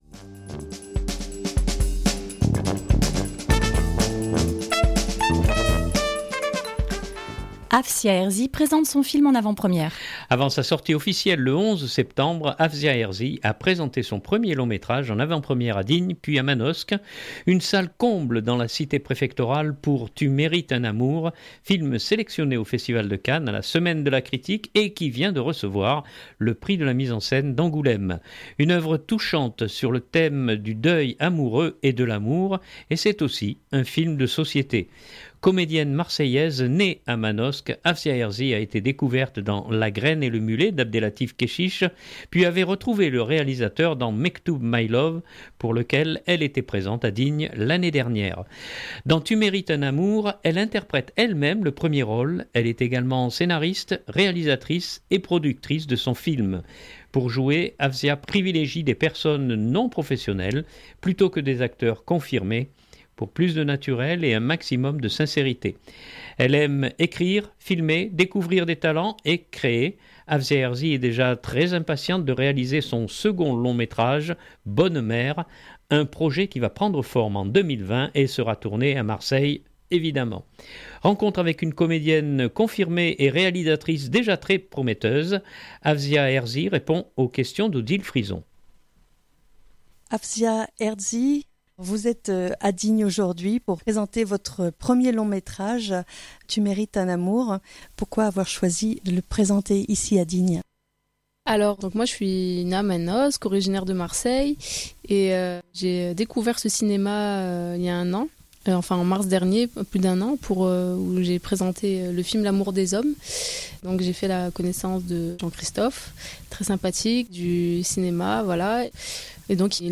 Rencontre avec une comédienne confirmée et réalisatrice déjà très prometteuse.